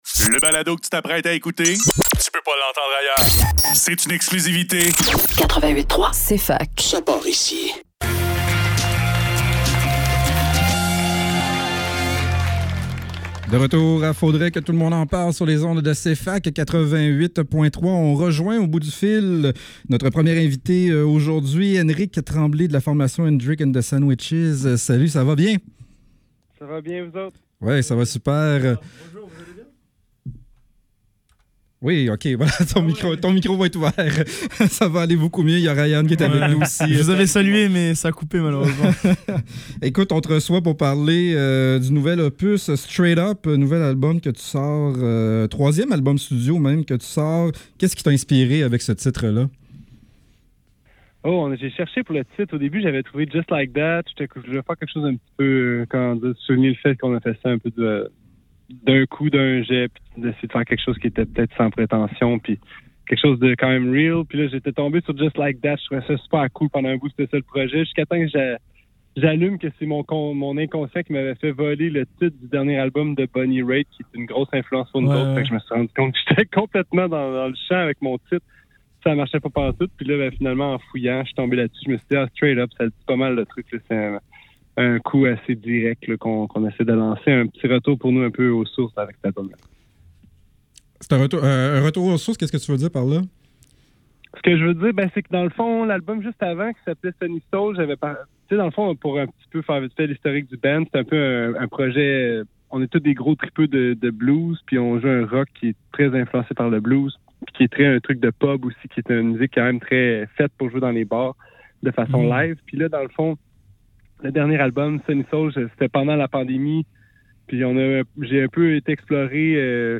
Faudrait que tout l'monde en parle - Entrevue avec Endrick and the Sandwiches - 24 septembre 2024